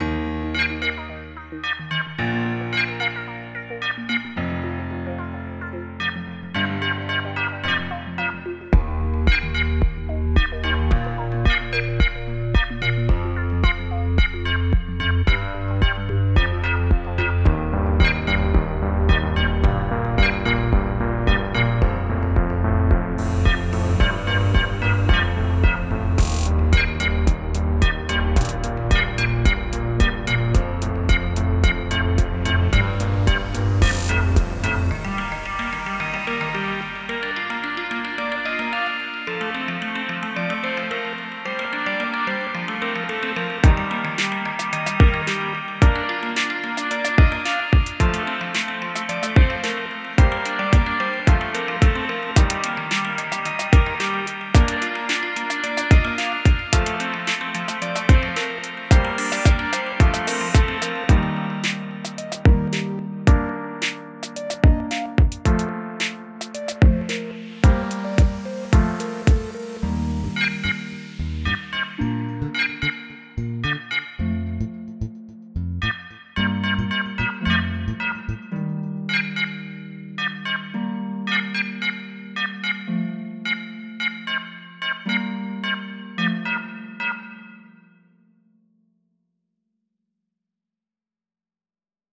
Convert any audio clip into a synthetic instrument!